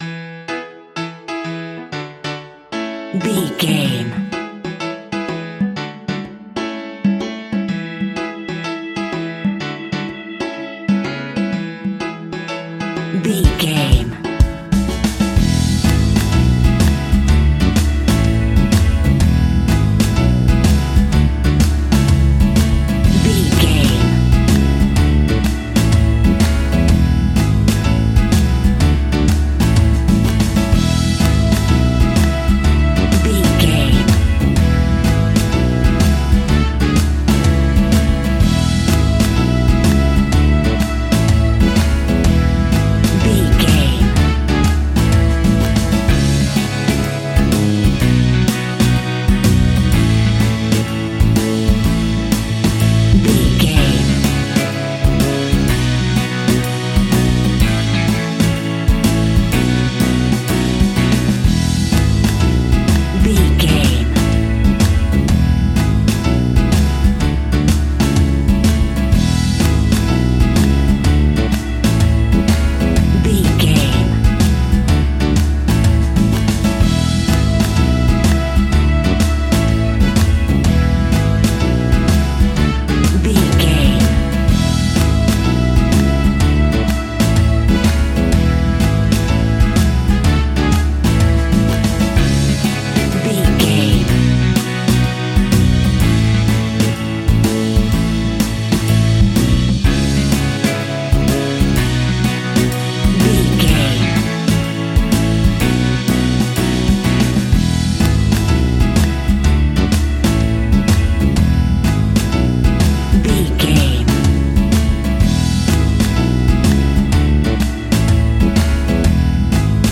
Pop Music for Adverts.
Ionian/Major
cheesy
electro pop
pop rock
synth pop
happy
peppy
upbeat
bright
bouncy
drums
bass guitar
electric guitar
keyboards
hammond organ
acoustic guitar
percussion